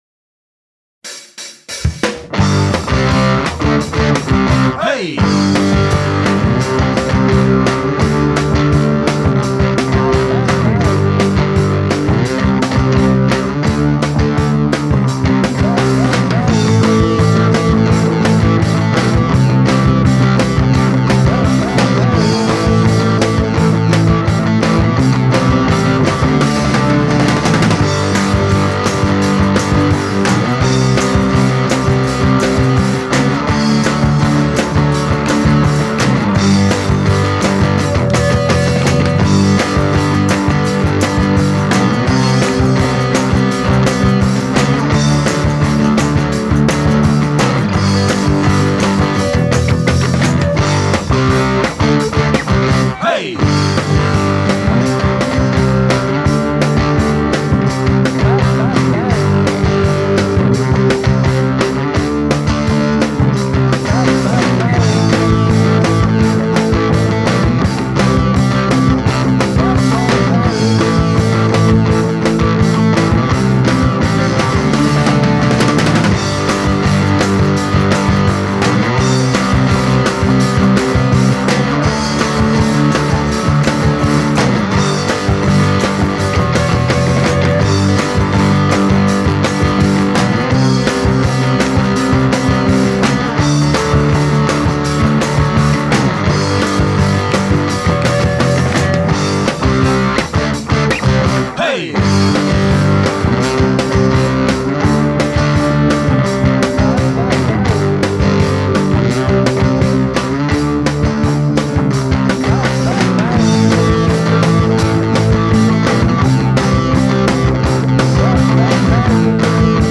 Es más guitarrera y cañera.
y_vine_al_jamc1en_KARAOKE.mp3